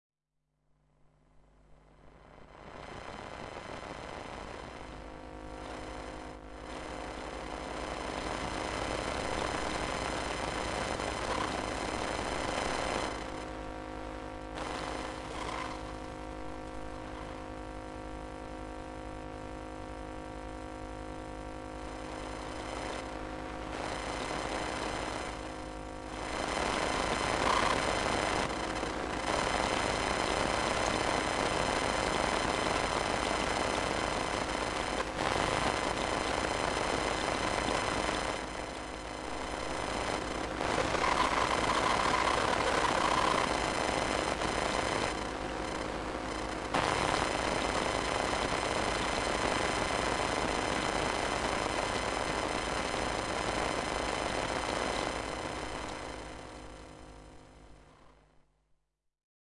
电磁场 " 笔记本电脑冷却器
描述：使用Zoom H1和电磁拾音器录制